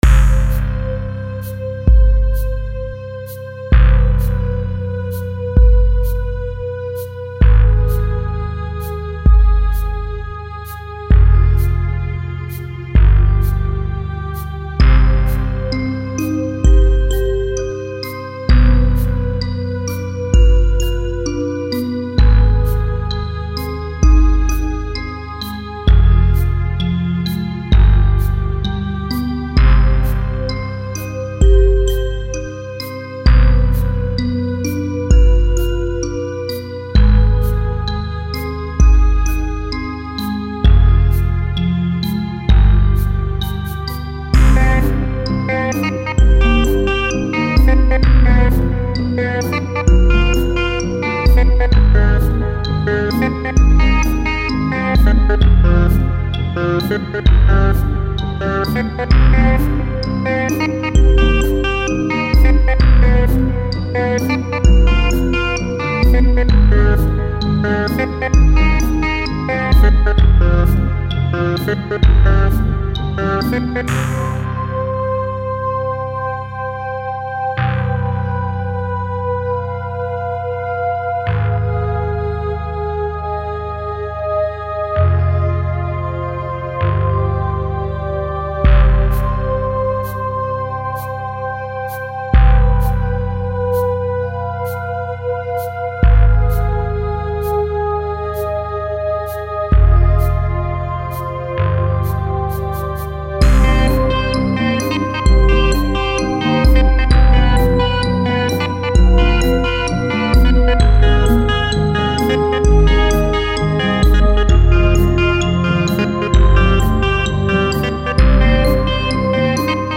Spooky Tune
spooky_tune_1.mp3